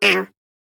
Sfx_tool_spypenguin_vo_hit_wall_07.ogg